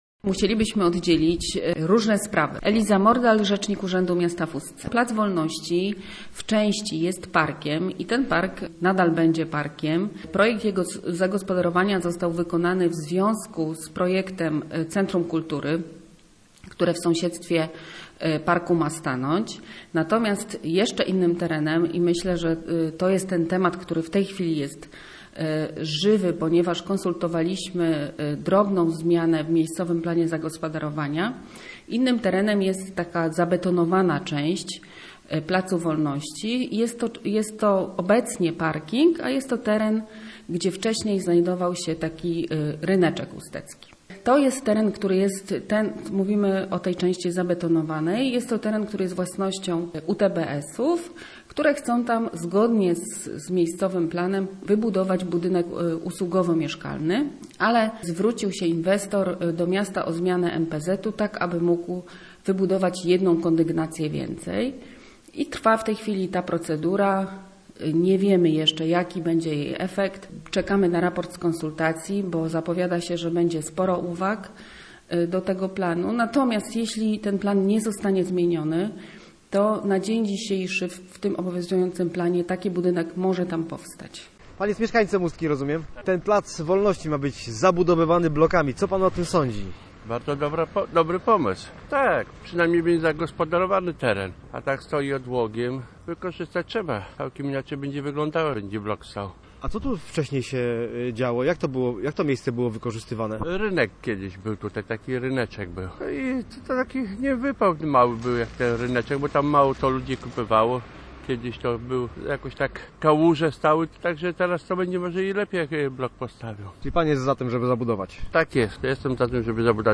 Posłuchaj materiału reportera Radia Gdańsk: https